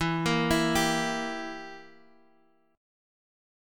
Edim Chord